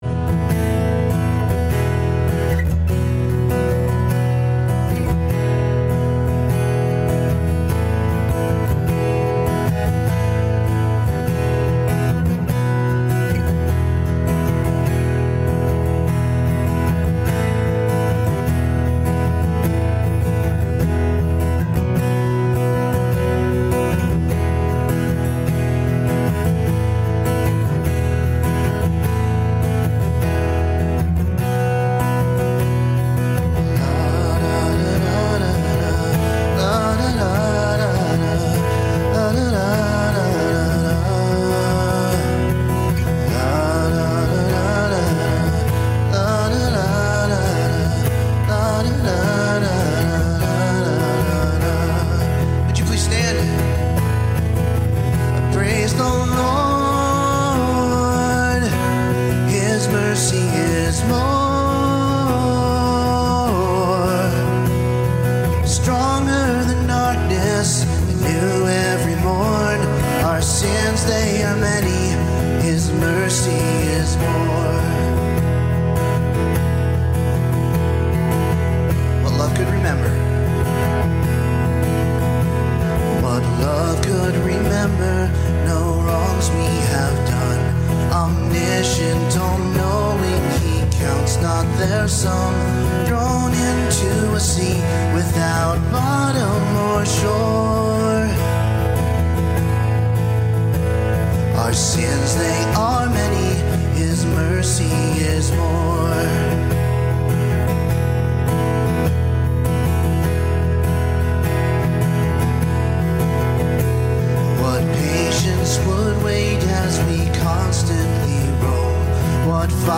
Passage: John 13:36-38, 21:15-19 Service Type: Sunday Morning